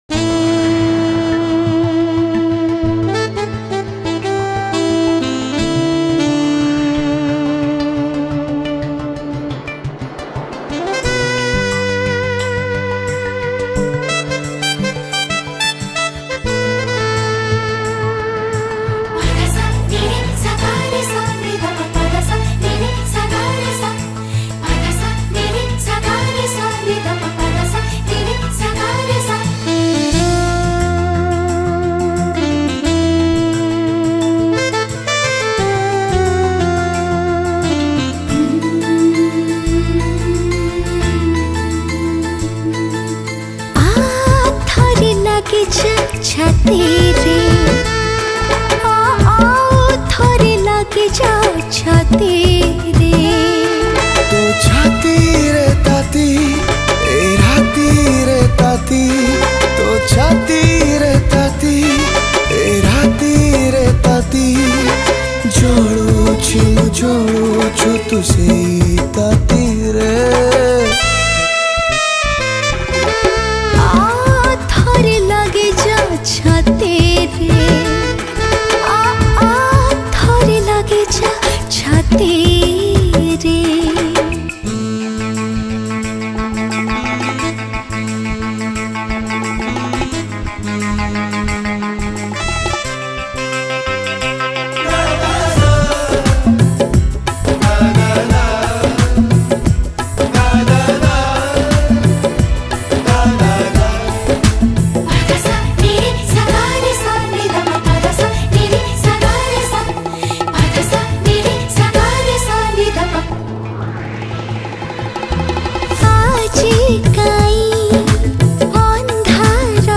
Odia Jatra Songs Download